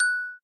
bell.ogg